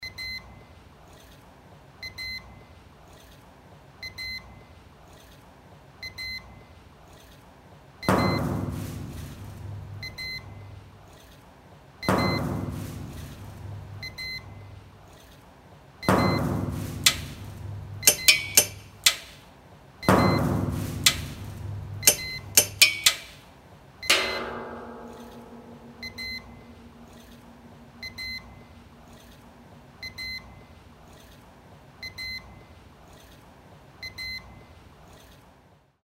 サウンドコラージュの制作
中心となるリズムを作ってみよう
制作の流れ 撮影 音が出ているところを余韻まで含めて撮影しよう 少なくとも10個くらい使えそうな音は録画(録音)しよう 準備 作業フォルダを作り、その中に「mov」,「mov_unused」フォルダを作成する iPhoneならAirDropで転送してください。
dd2-soundcollage.mp3